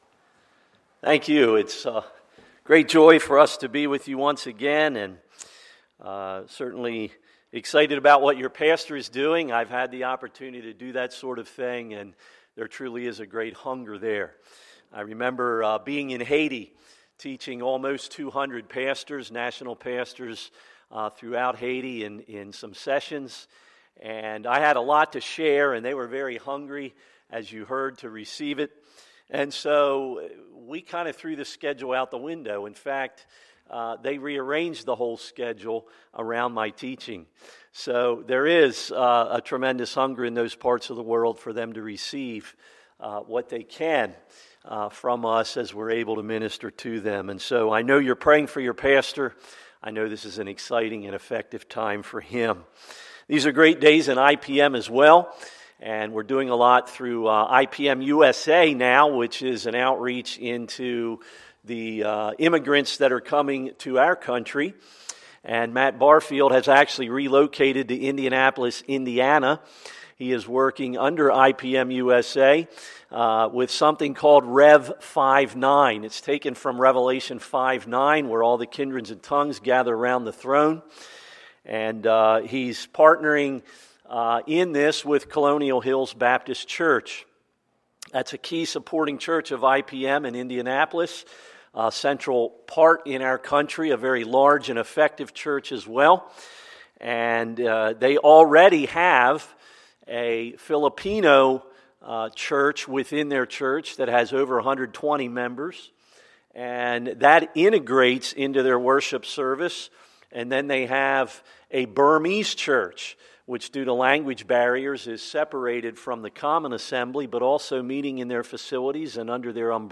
Sunday, August 7, 2016 – Sunday Morning Service